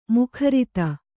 ♪ mukharita